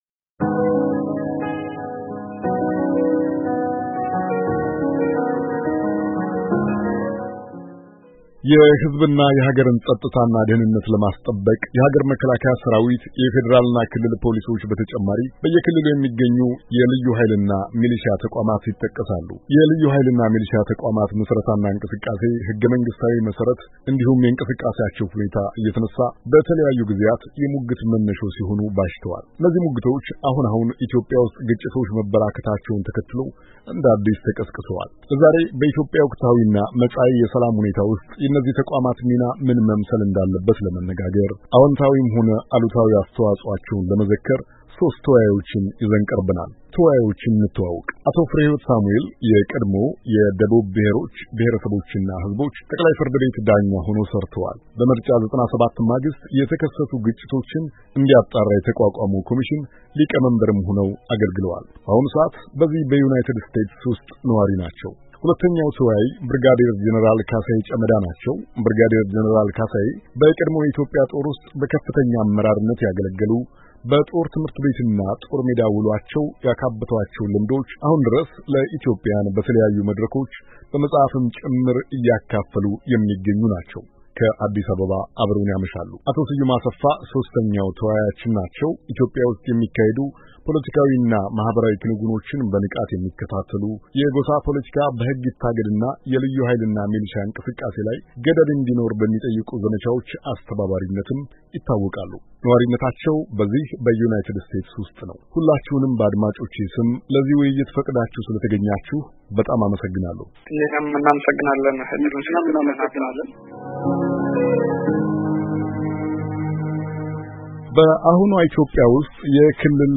የክልል ልዩ ኃይሎች አነጋጋሪ ምስረታ እና ስጋት(ውይይት)